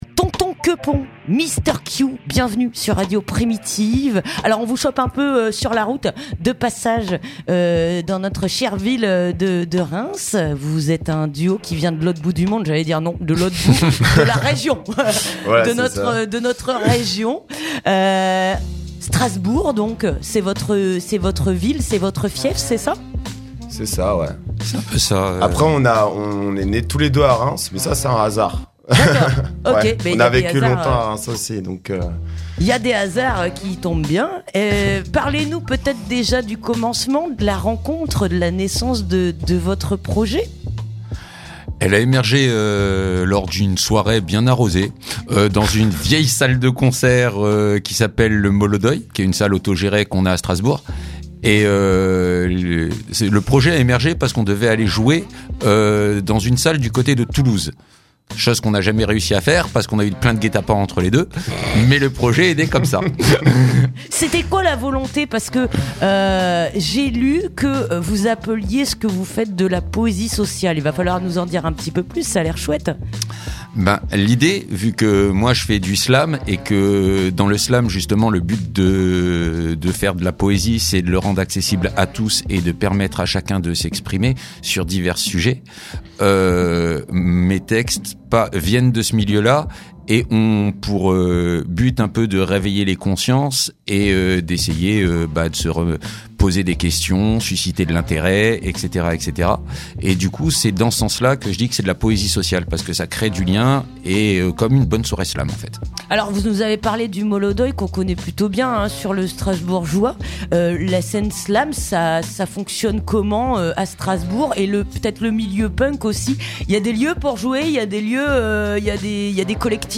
Interview du duo (11:58)